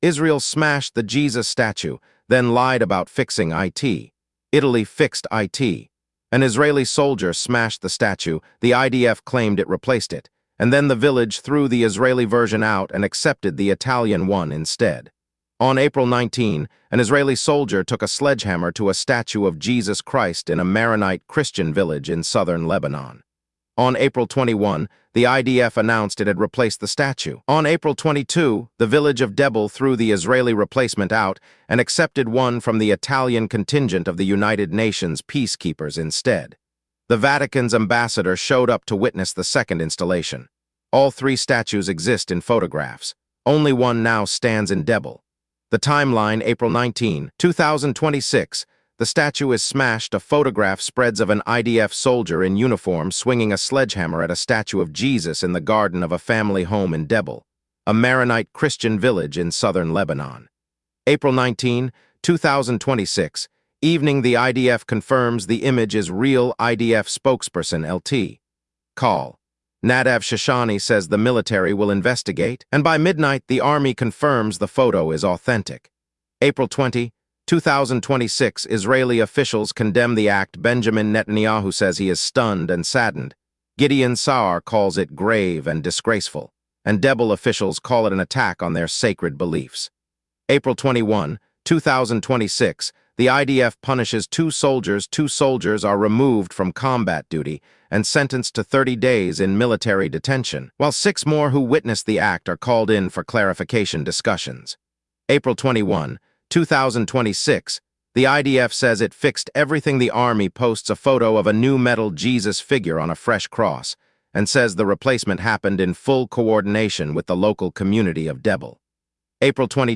Read this article aloud